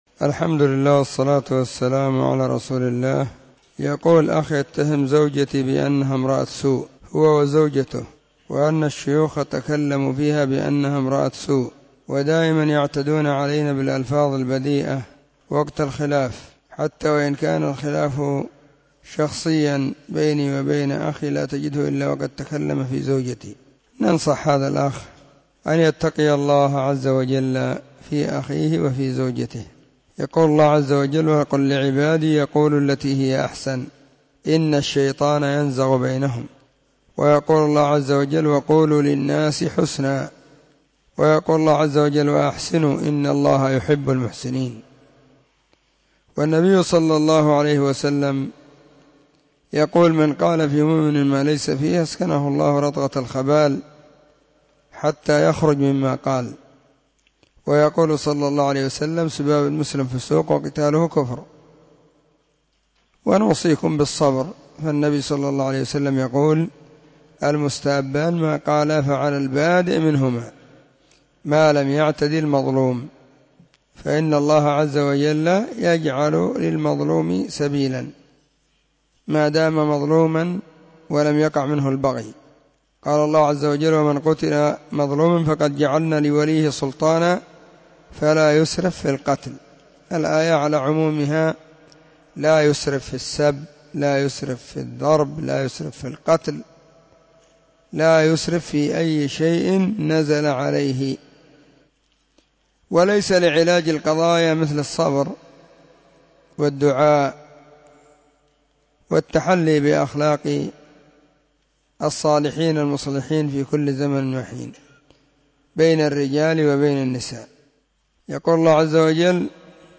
فتاوى ,الأحد 26 /صفر/ 1443 هجرية., ⭕ أسئلة ⭕ -7